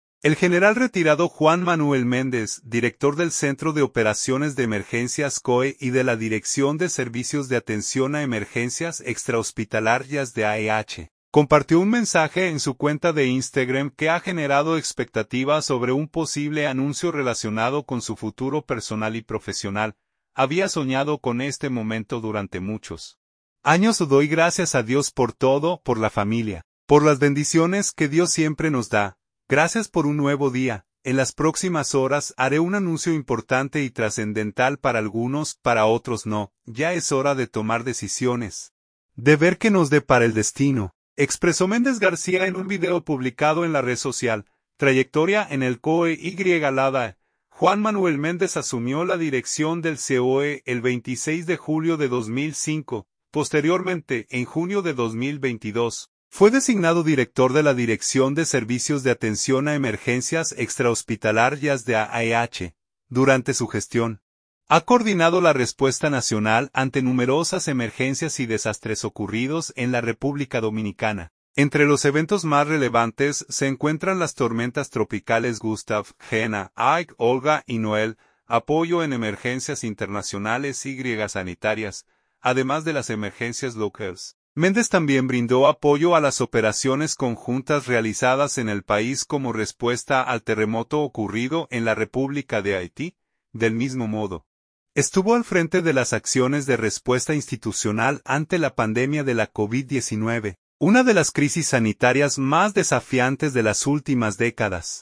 En las próximas horas haré un anuncio importante y trascendental para algunos, para otros no. Ya es hora de tomar decisiones, de ver qué nos depara el destino”, expresó Méndez García en un video publicado en la red social.